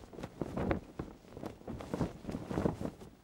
cloth_sail7.R.wav